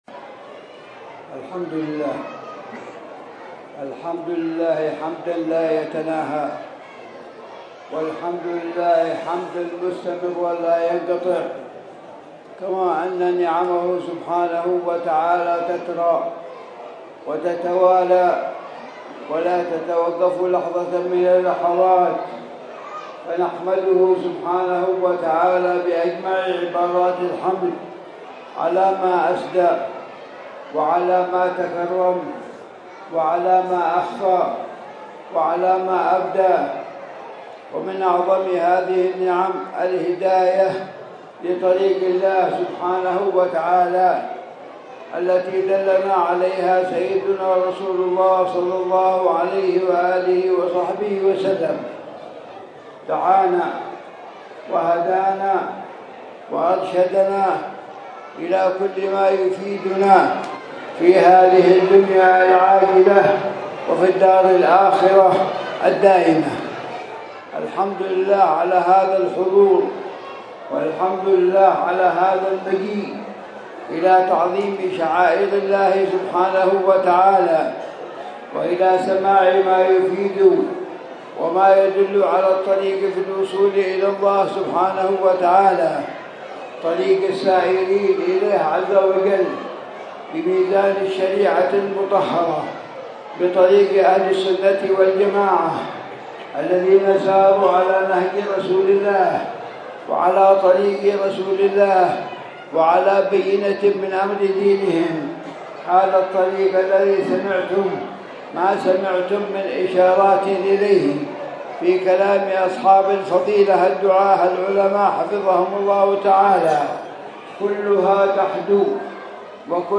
مذاكرة